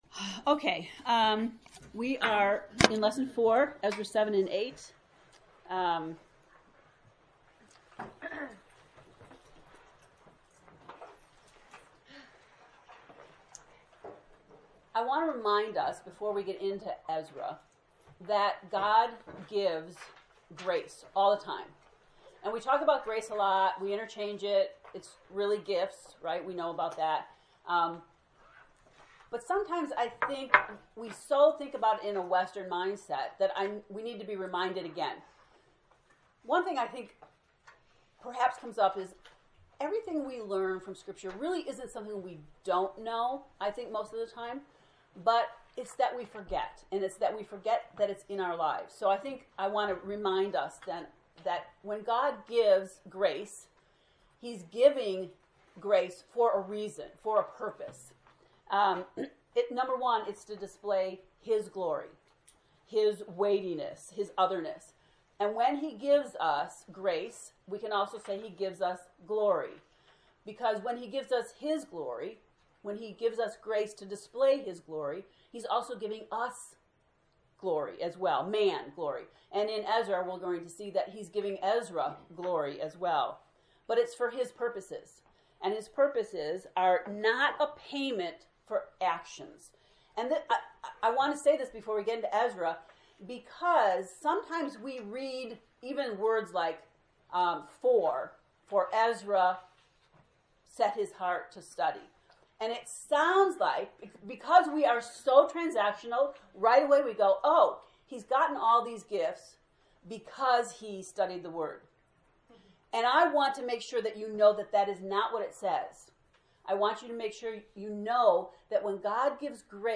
EZRA lesson 4